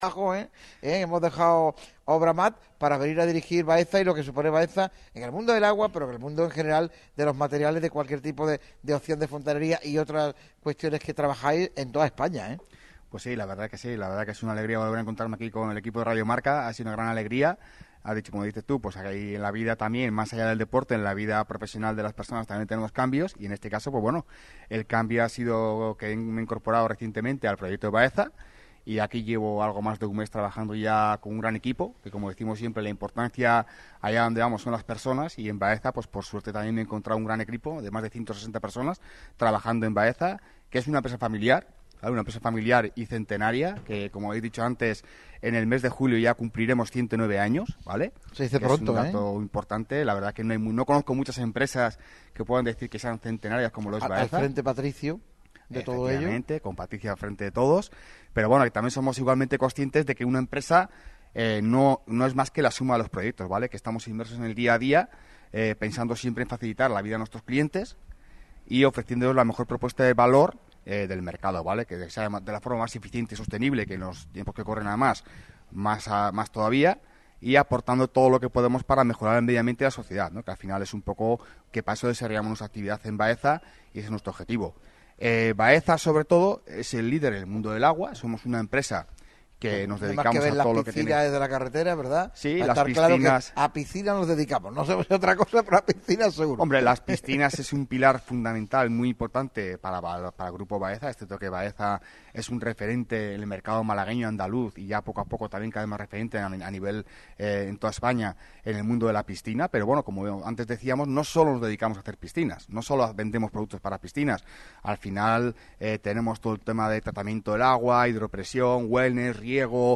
su equipo recibe a la redacción en este Lunes Santo
y sobre todo en un entorno inmejorable, justo en el corazón de la avenida Velázquez, próximo al aeropuerto.
En una tertulia de comité de sabios